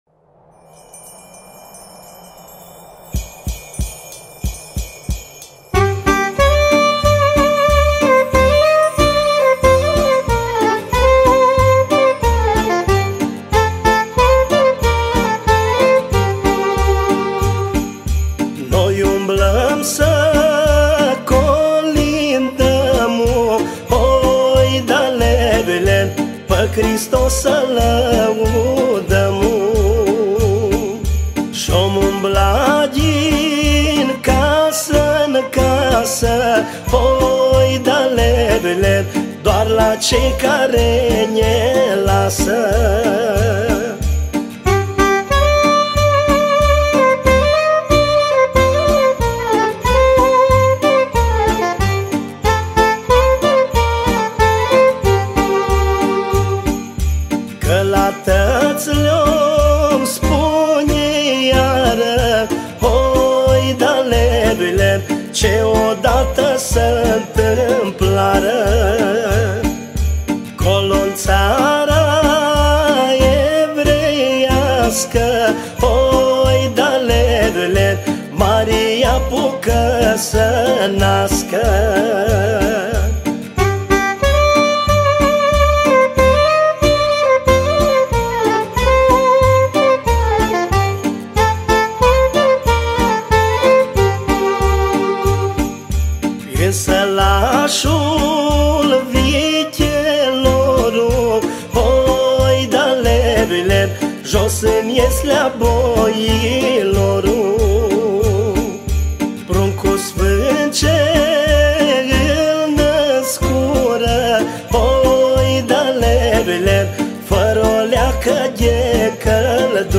Data: 13.10.2024  Colinde Craciun Hits: 0